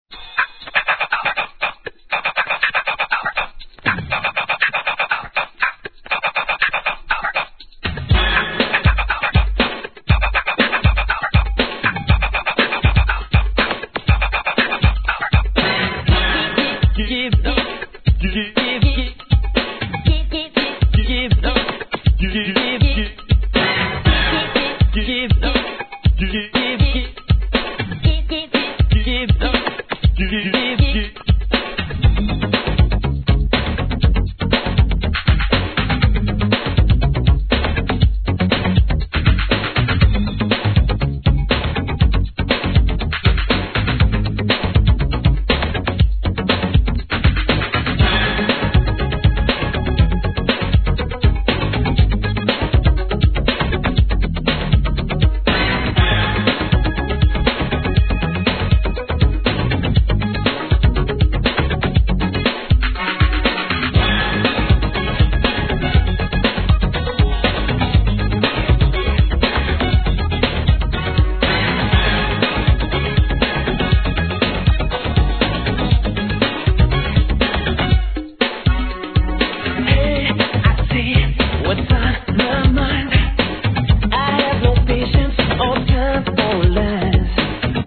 HIP HOP/R&B
NEW JACK SWING調に跳ねたダンスナンバー!!